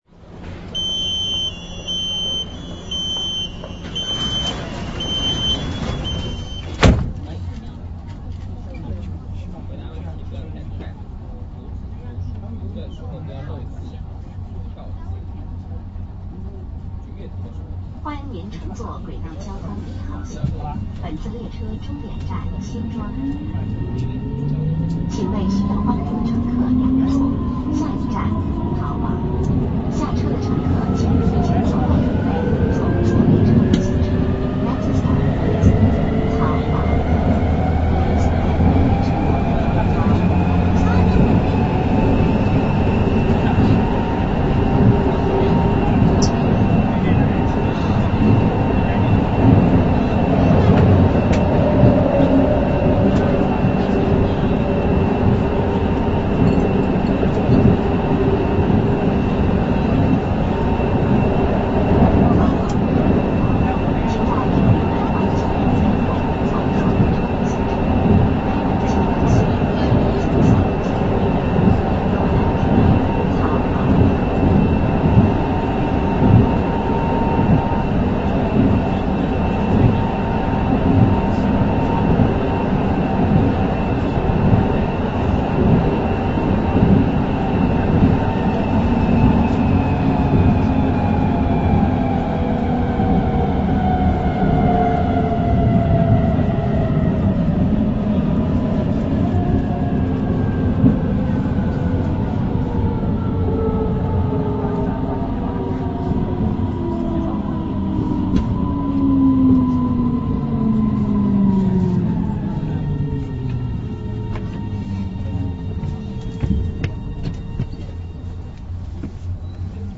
1号線DC01B型電車走行音（衡山路→徐家匯） IGBT-VVVF制御で、制御装置は株洲南車時代電気製。
Tc-M-M-M-M-M-M-Tc（6M2T）の8両編成。
磁励音はシーメンス製のIGBT-VVVFに類似している。